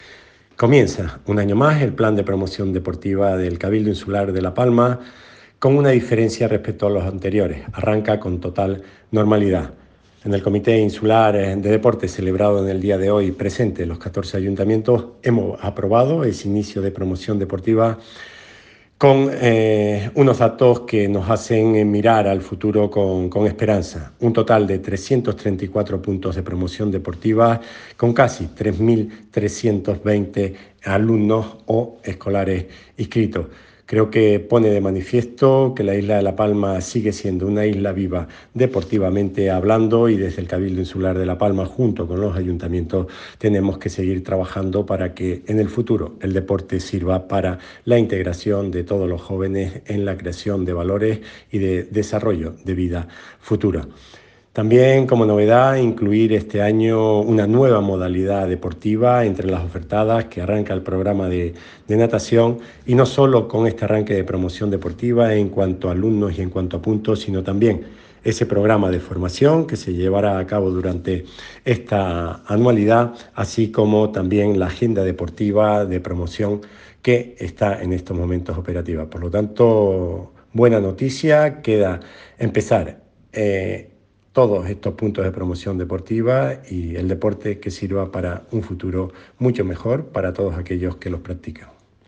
Declaraciones Raúl Camacho.mp3